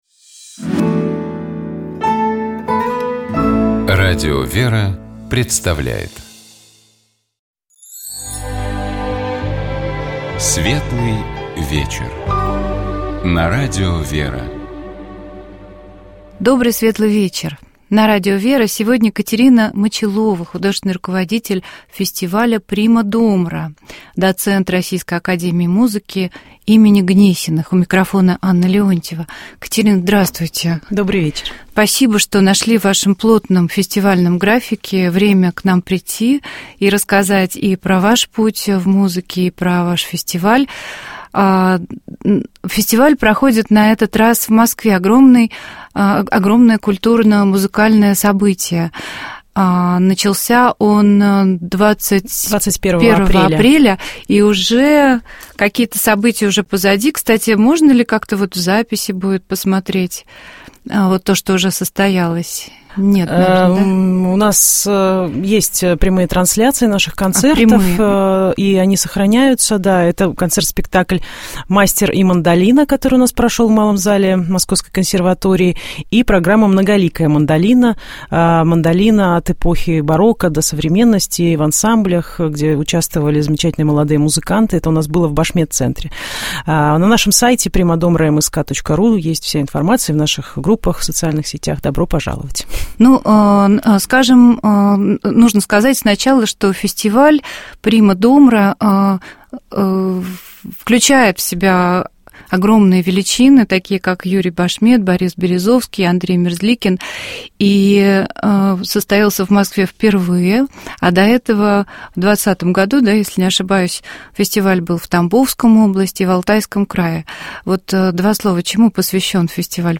Гостьей рубрики «Вера и дело» была депутат Государственной Думы, председатель Общероссийской общественной организации «Совет матерей» Татьяна Буцкая.